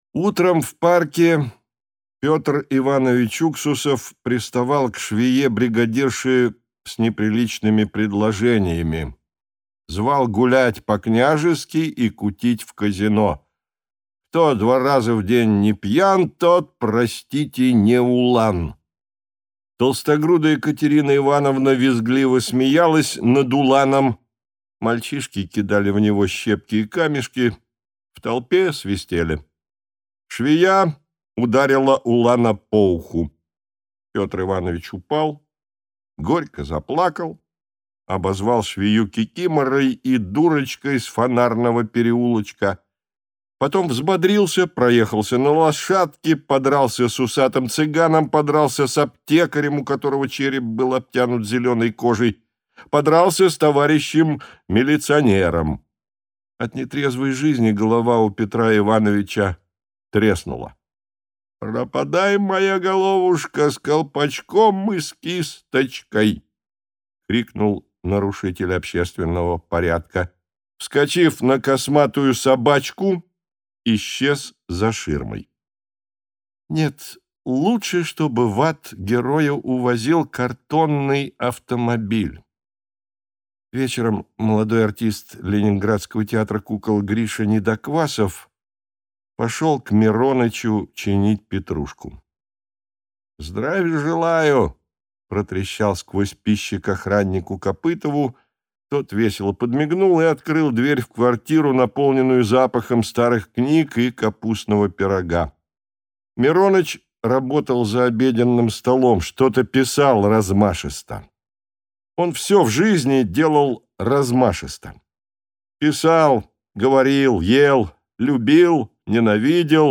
Аудиокнига Сияние «жеможаха». Гриша Недоквасов | Библиотека аудиокниг